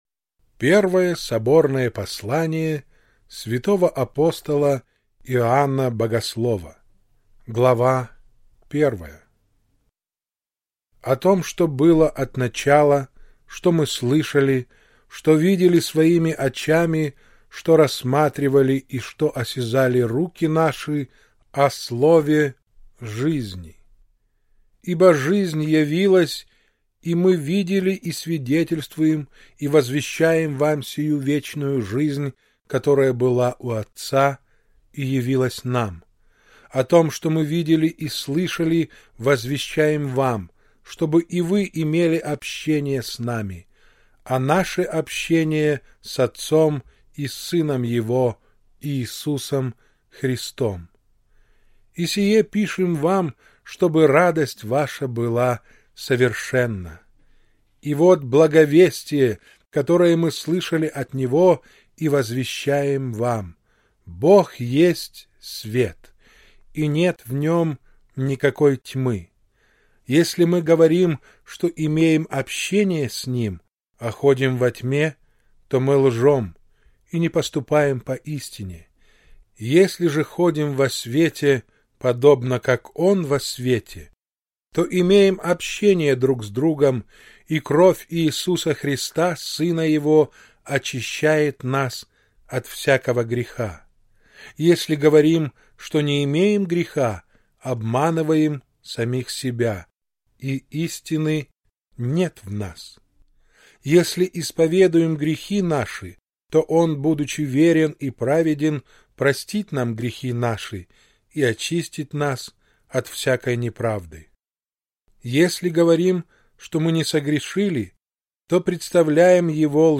Читает заслуженный артист